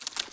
F_TWIG1.WAV